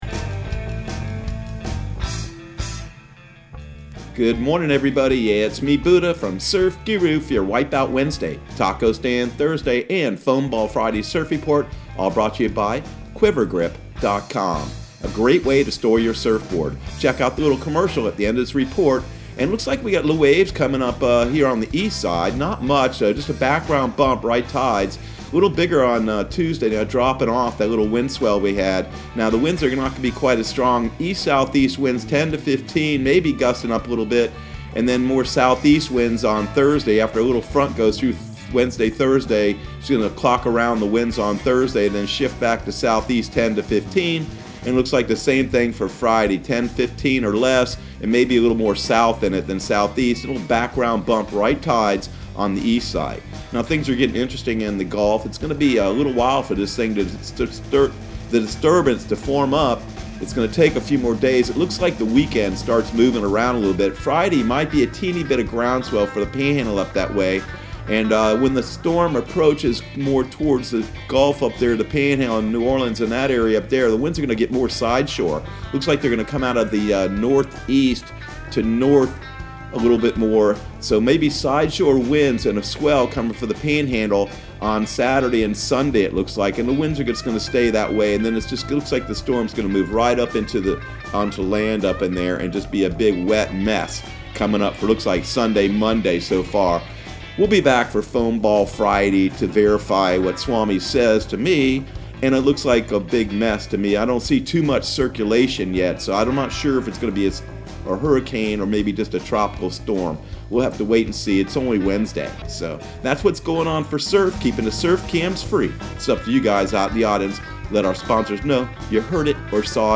Surf Guru Surf Report and Forecast 06/03/2020 Audio surf report and surf forecast on June 03 for Central Florida and the Southeast.